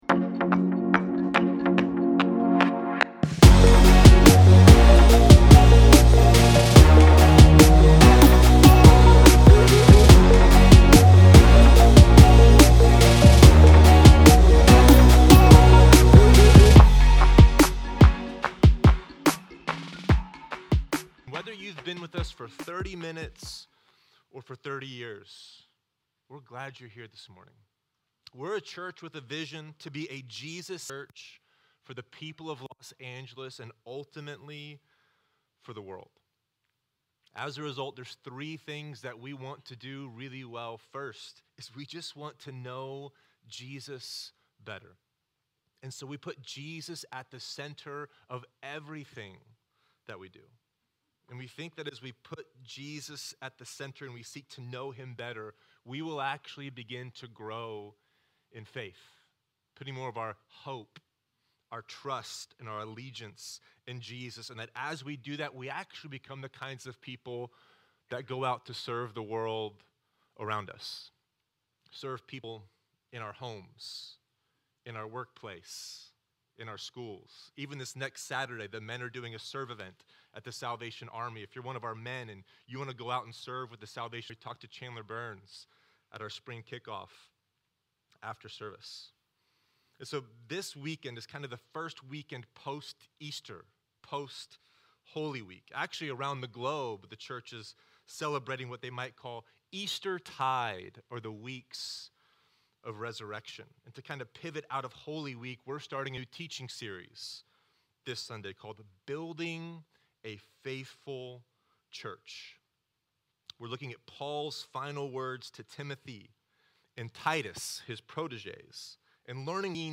*DUE TO TECHNICAL DIFFICULTIES: SOME AUDIO MISSING